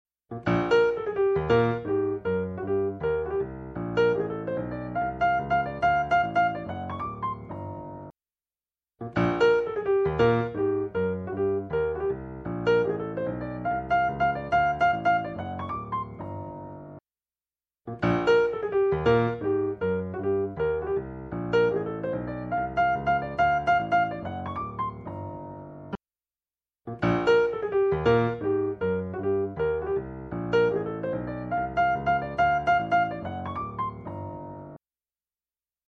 In each of the following listening tests you will hear four playbacks of a musical performance clip. Three of the clips were recorded with vintage Neve 1073 modules and one of the clips was recorded with a Vintech X73 preamp. In each case one mic was passively split to all four preamps so that each preamp could amplify the same exact performance utilizing the same exact microphone. The only variable is the preamps.
Acoustic Piano
pianotest.mp3